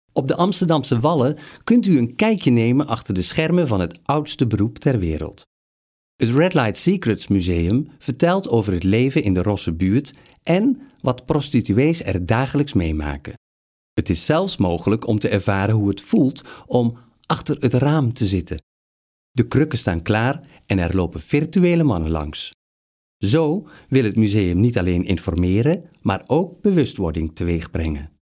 Klankkleur & Stemdemo’s
Empatisch, enthousiast, iemand met een geloofwaardige stem.
AV publieksinfo, Amsterdamse Wallen